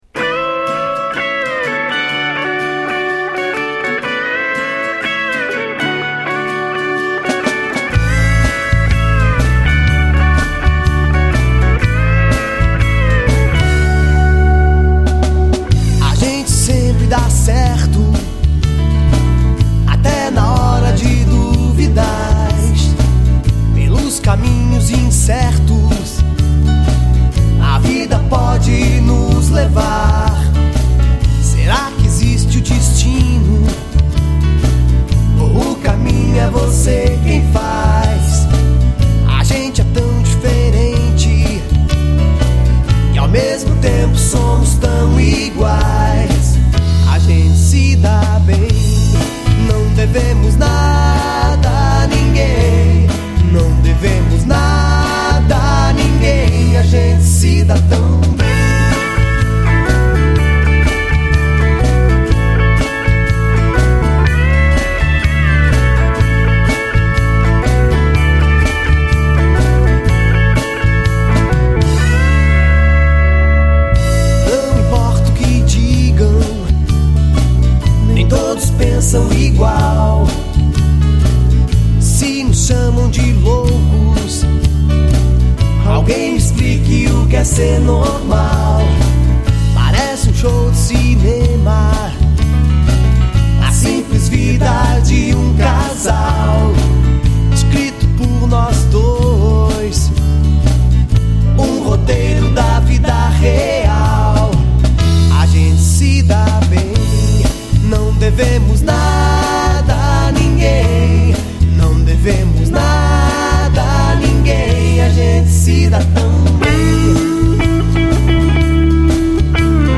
EstiloPop Rock